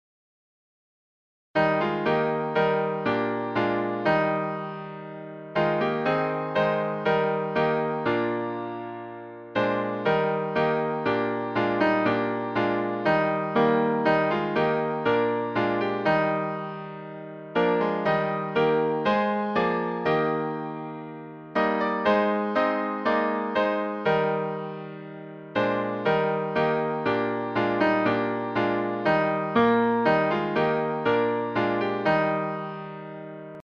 Hymns of praise
Music by: Trad. English melody;